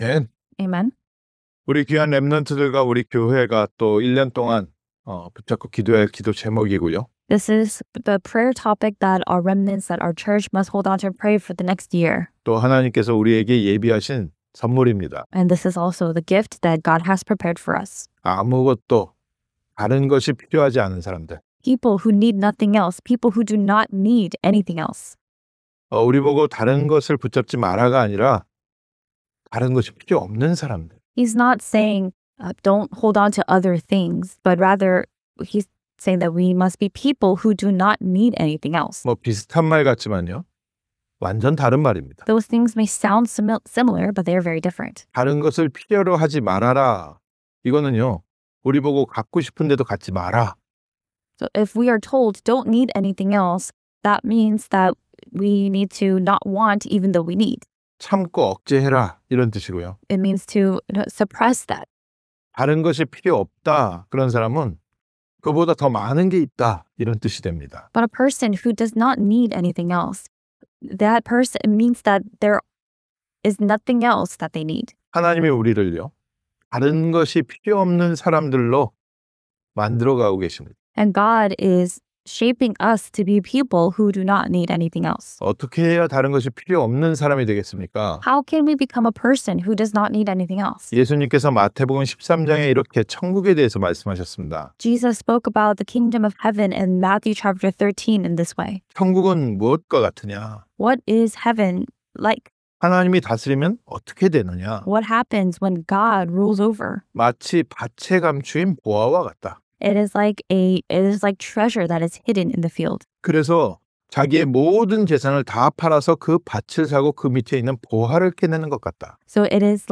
2025 Remnant Conference in America